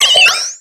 Cri de Tiplouf dans Pokémon X et Y.